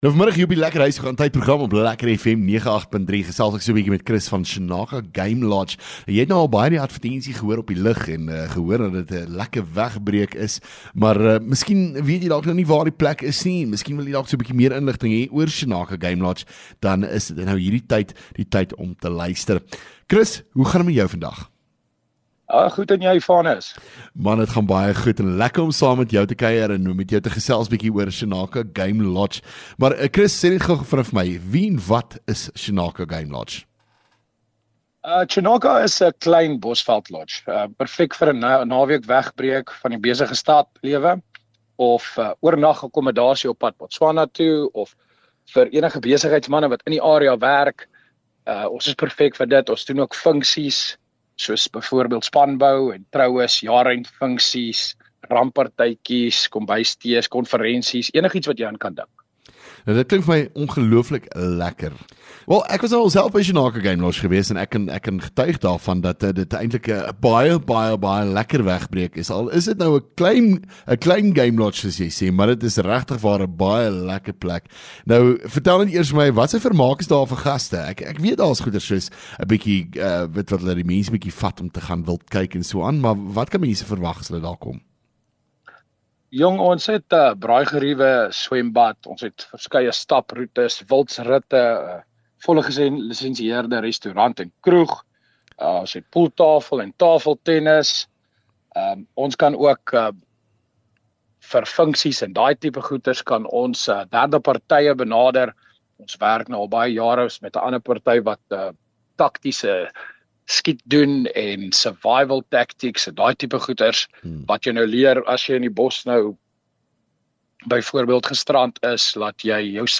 LEKKER FM | Onderhoude 23 Jan Chinaka Game Lodge